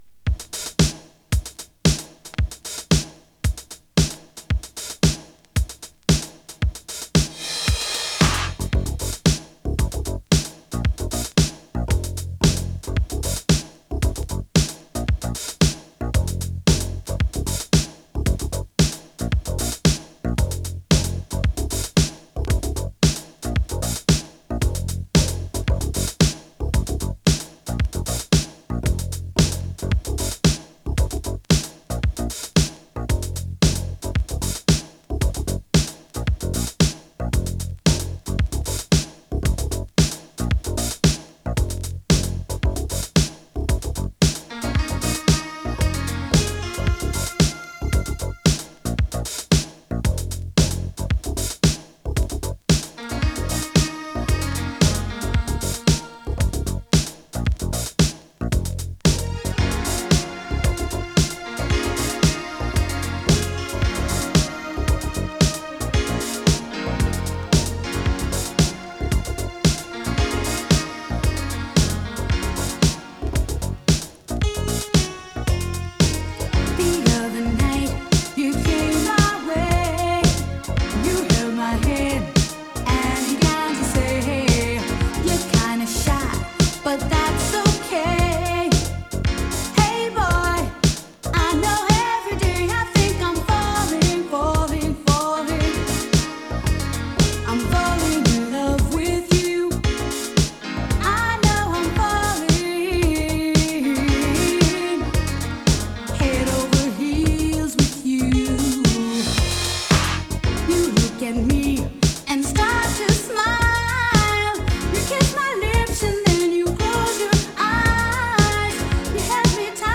ニュージャージー出身の女性シンガーによる